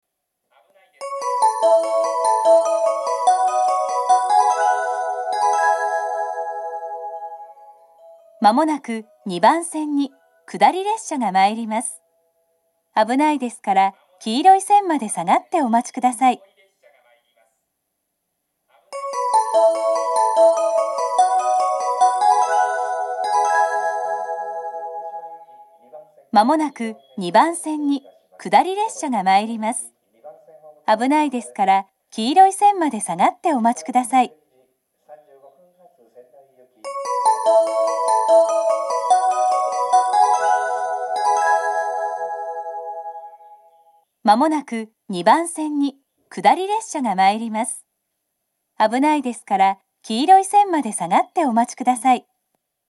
この駅の放送は東北でよく聞ける放送ではなく、カンノの放送です。接近放送は１・２番線は２回、３番線は３回流れます。
２番線下り接近放送